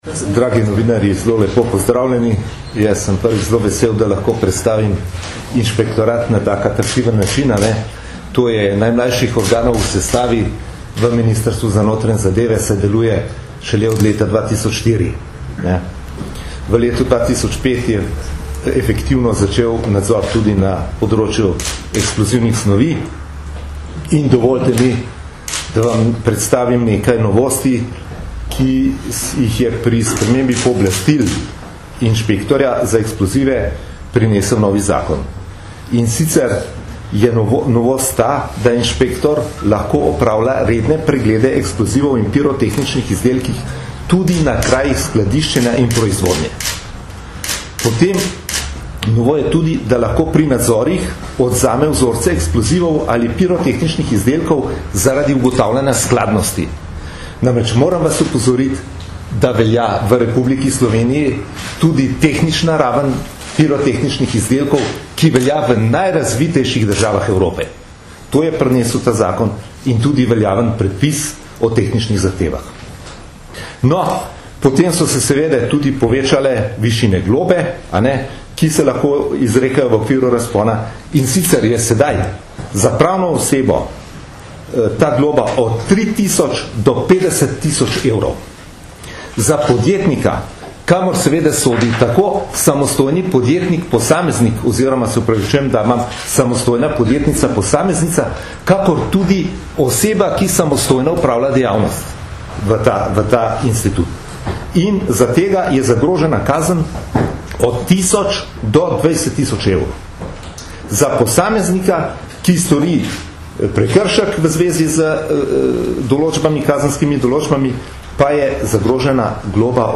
Na današnji novinarski konferenci so predstavniki policije in inšpektorata Republike Slovenije za notranje zadeve na kratko predstavili novosti, ki jih prinaša novi Zakon o eksplozivih in pirotehničnih izdelkih.